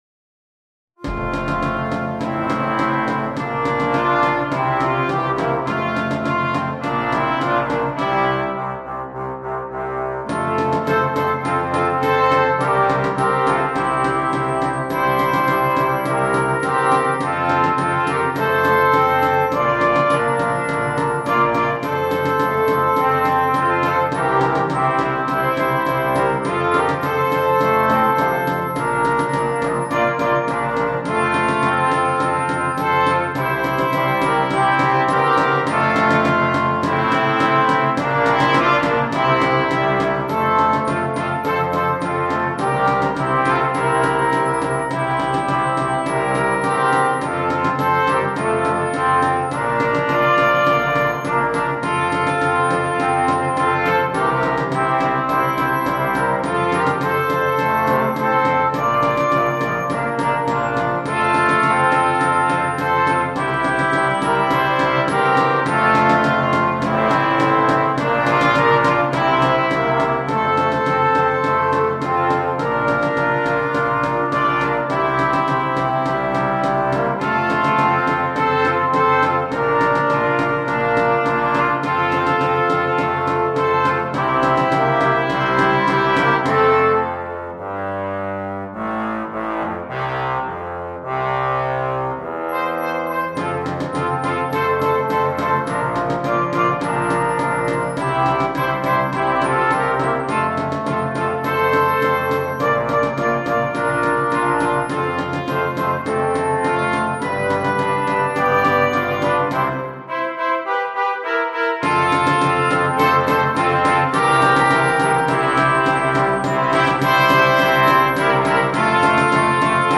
2. Orchestre Juniors (flex)
4 parties et percussions
sans instrument solo
Musique légère
Percussion